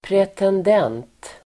Ladda ner uttalet
pretendent substantiv, pretender Uttal: [pretend'en:t] Böjningar: pretendenten, pretendenter Definition: person som gör anspråk på något (aspirant, claimant) Sammansättningar: tronpretendent (pretender to the throne)